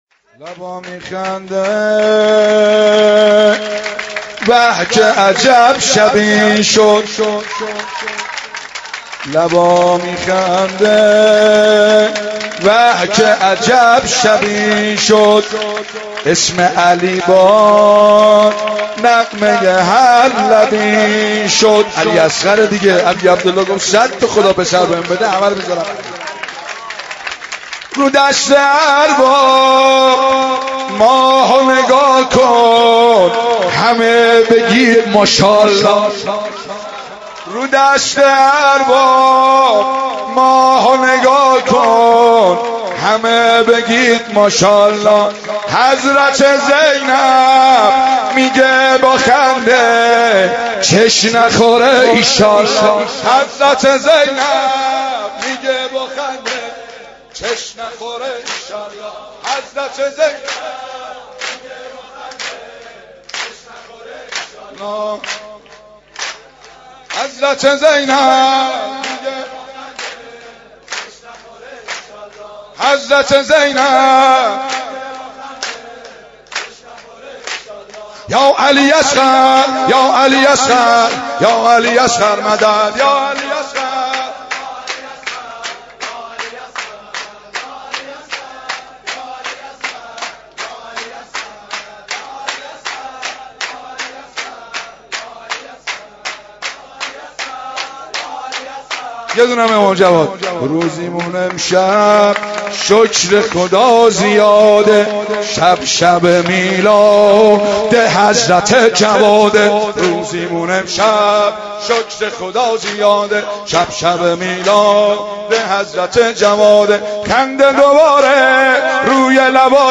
ولادت امام جواد (ع) 91 - سرود - لبا میخنده به که عجب شبی شد